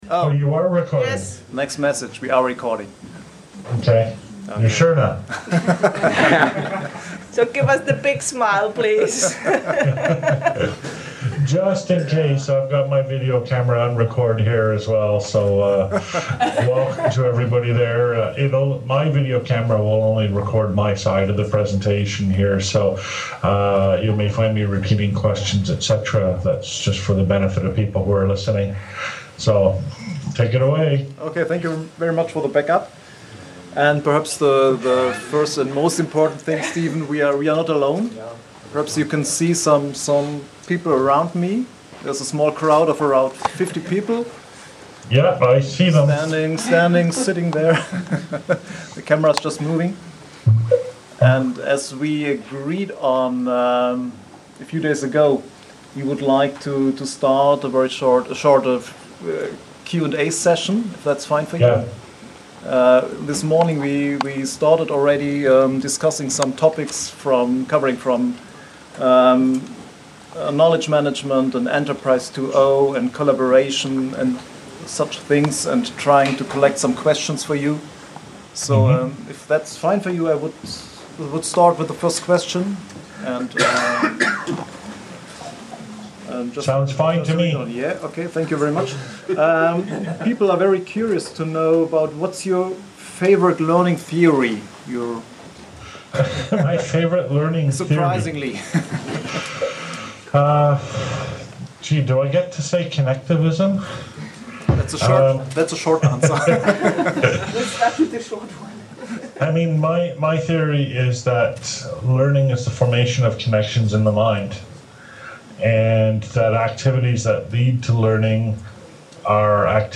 Skype, Interview